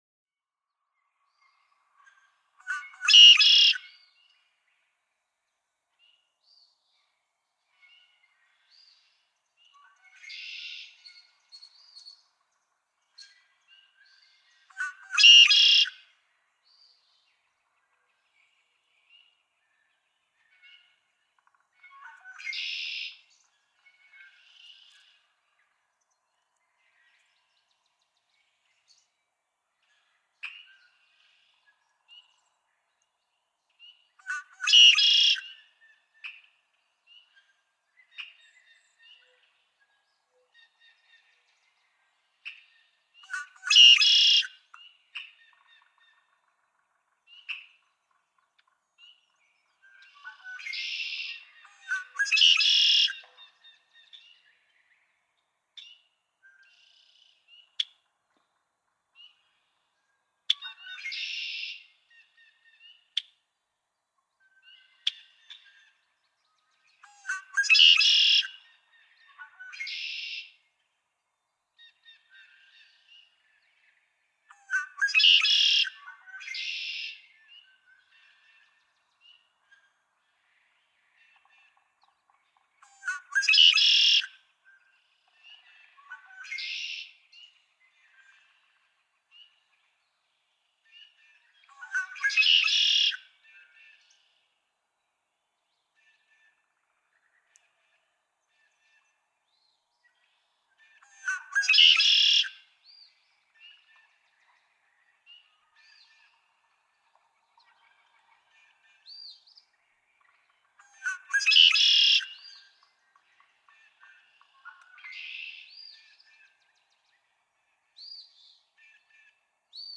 Red-winged blackbird
♫55. Male songs, in the minutes before the female first appears on the territory in the morning (recording continued in ♫56 below). Note a second male singing in the background (e.g., 0:10, 0:22, 0:50, etc.); hear also how the foreground male changes to a different song at 2:18.
Atlanta, Michigan.
055_Red-winged_Blackbird.mp3